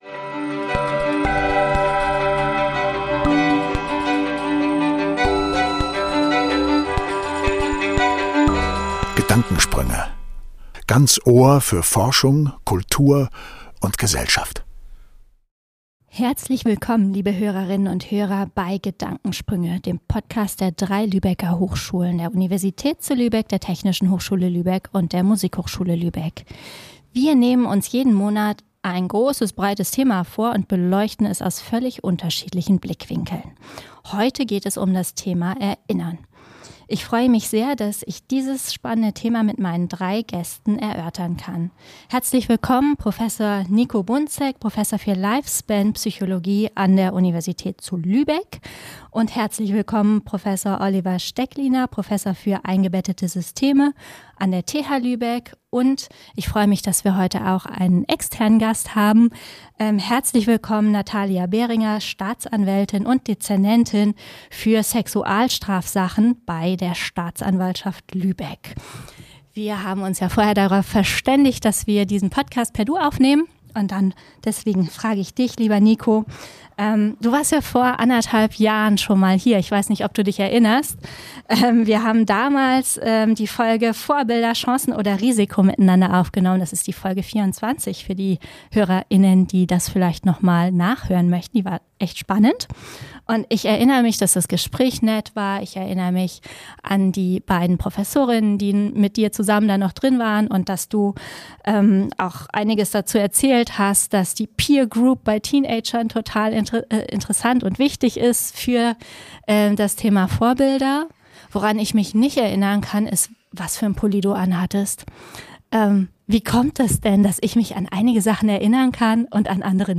Warum trügt uns unser Gedächtnis manchmal, warum erinnern sich zwei Menschen völlig unterschiedlich an das gleiche Ereignis, und gibt es vielleicht auch ein Recht auf Vergessen? Darüber sprechen ein Informatiker, ein Psychologe und eine Staatsanwältin miteinander.